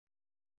♪ ṛoppu